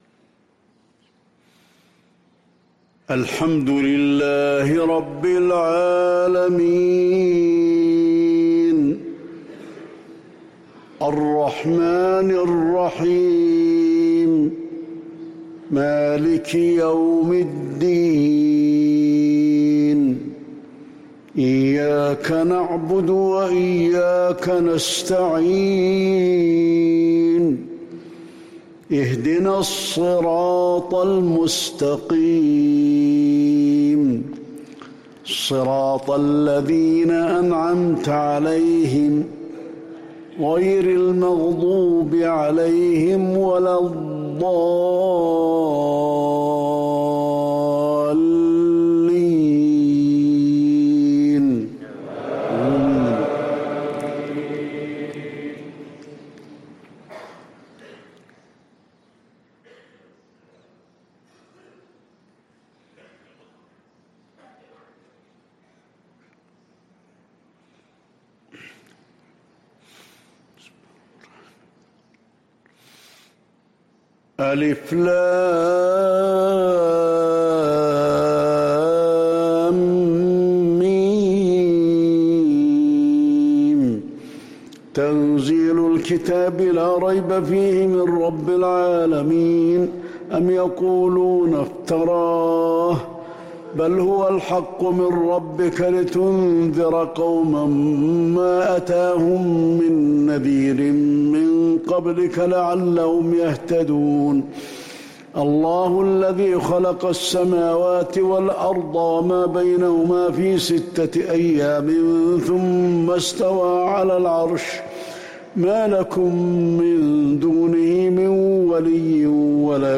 فجر الجمعة 7 محرم 1444هـ سورتي السجدة و الإنسان | Fajr prayer Surat Alsajdah and Alinsan 5-8-2022 > 1444 🕌 > الفروض - تلاوات الحرمين